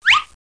00225_Sound_Zip.mp3